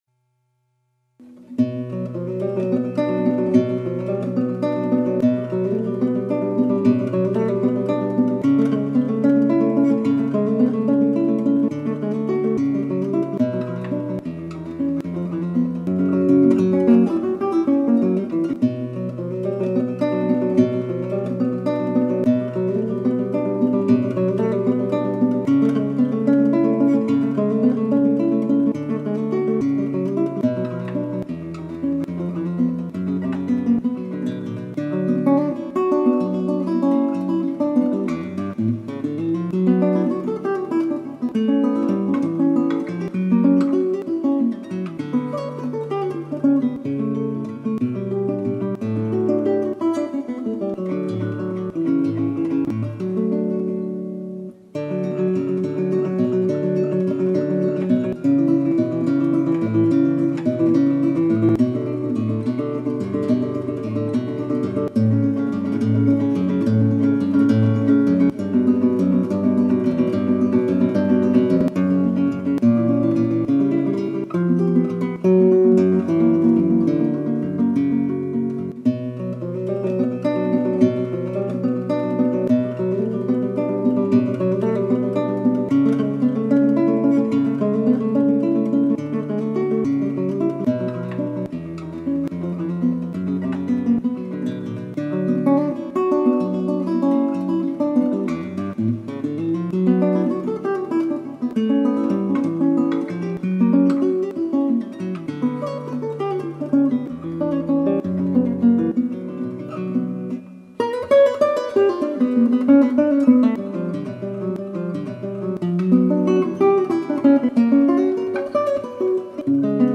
3. Allegro solemne : dồn dập mà nghiêm trang
Bản nhạc tuy sáng tác trong thế kỷ 20 tại Nam Mỹ, nhưng Barrios cố ư viết theo lối của J.S. Bach để tưởng niệm người nhạc sĩ đại tài của thế kỷ 18 này.